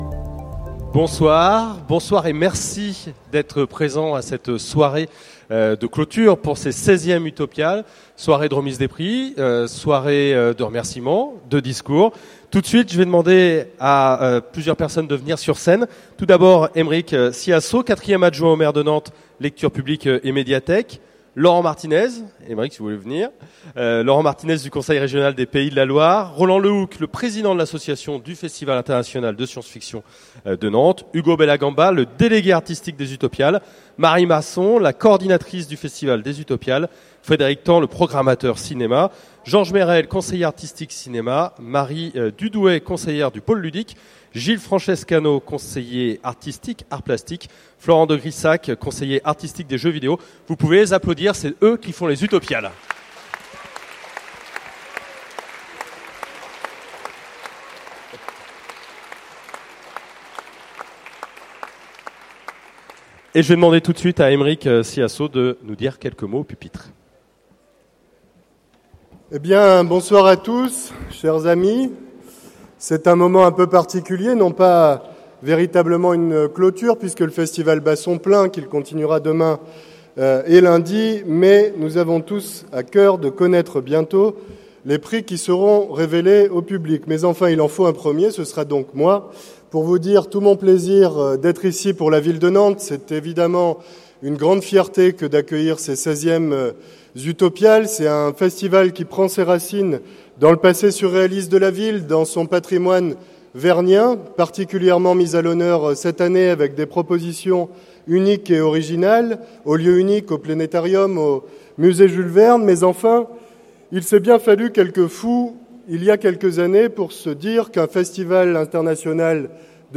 - le 31/10/2017 Partager Commenter Utopiales 2015 : Remise des prix Utopiales Télécharger le MP3 Remise prix Utopiales 2015 - Montage Vidéo Kizoa Genres / Mots-clés Remise de prix Conférence Partager cet article